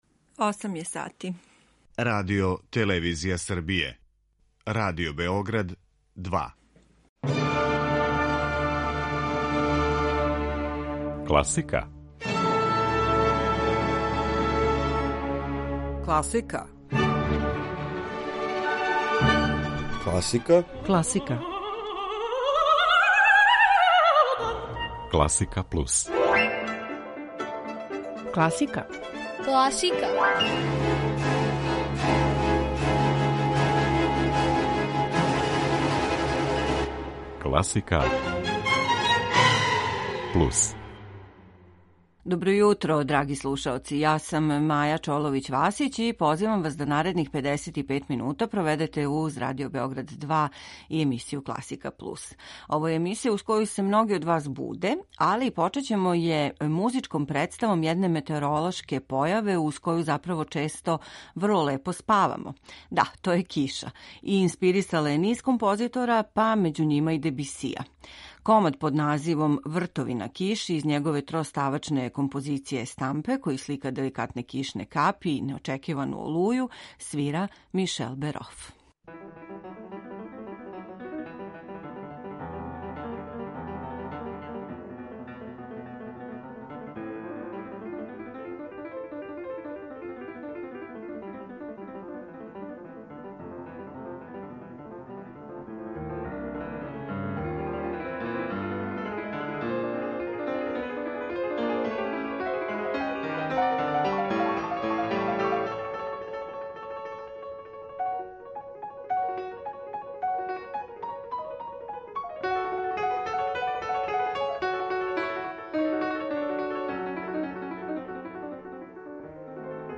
Још једно јутро испуњено које доноси разноврсни музички избор.
У рубрици "На други начин" Алисон Балсом на труби изводи један од виртузних аранжмана Жана Батиста Арбана.